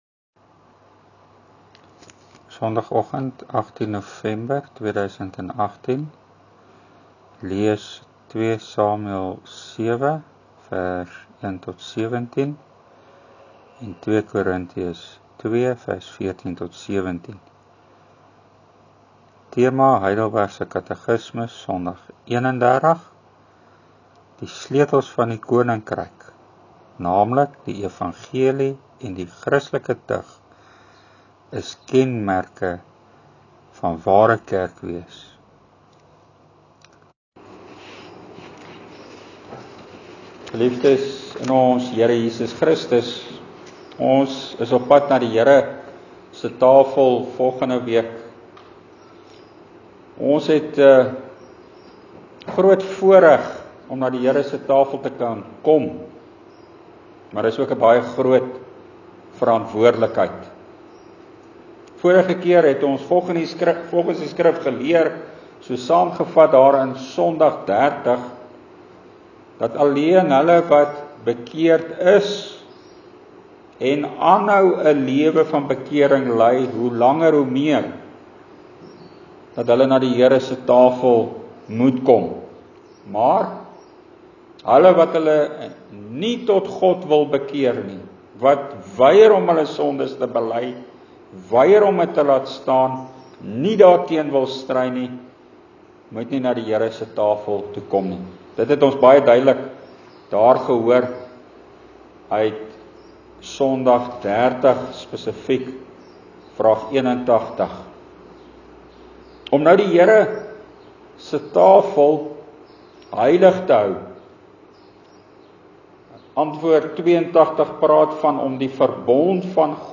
Preek: Is die Evangelie verkondiging en die Christelike tug ‘n eensnydende of tweesnydende swaard?